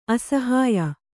♪ asahāya